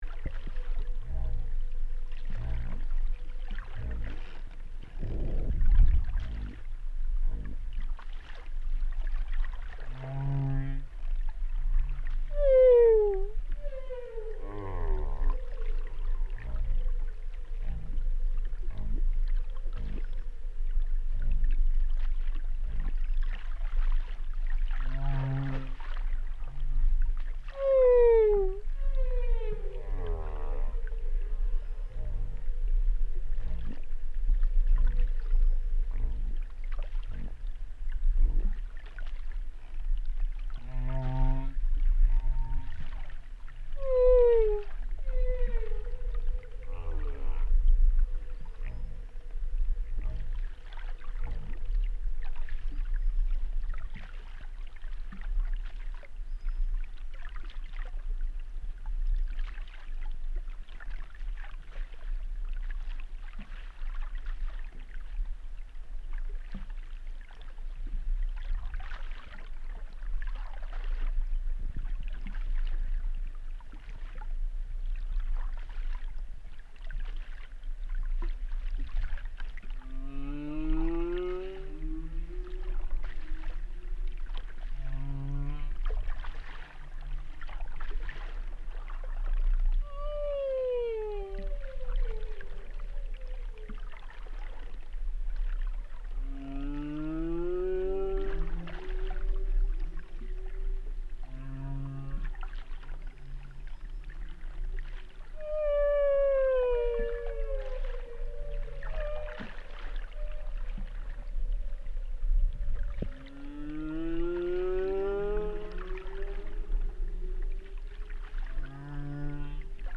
Chants-baleines-ShelltoneWhaleProject.mp3